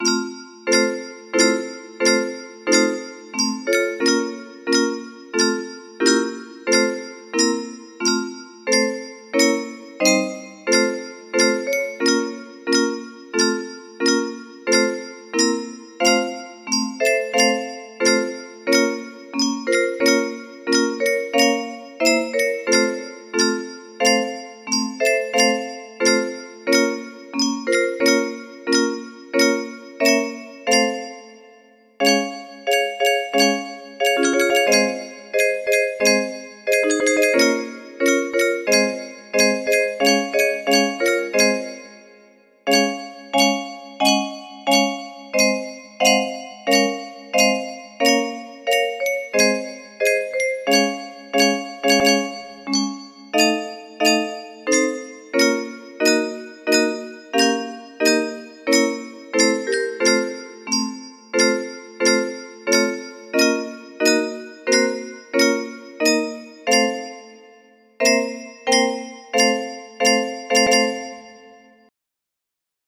Aram Khachaturian - State Anthem of the Armenian SSR music box melody
Full range 60